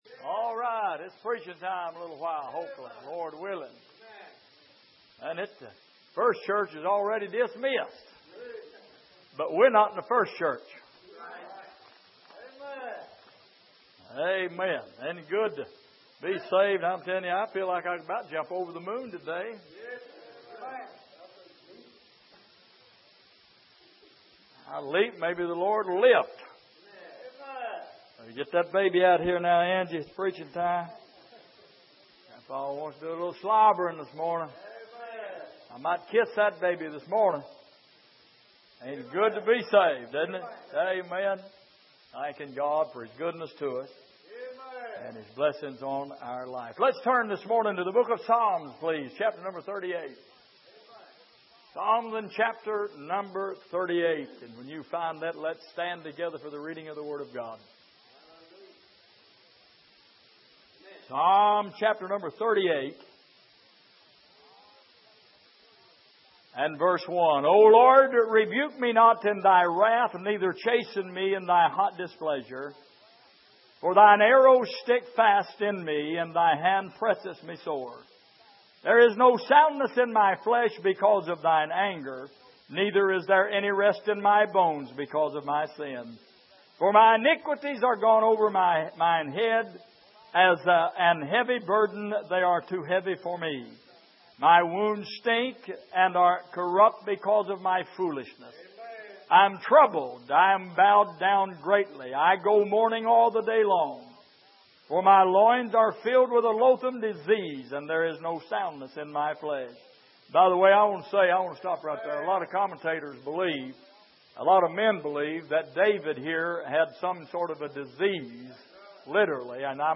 Passage: Psalm 38:1-22 Service: Sunday Morning